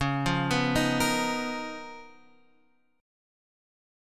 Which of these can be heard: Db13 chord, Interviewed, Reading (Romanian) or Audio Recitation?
Db13 chord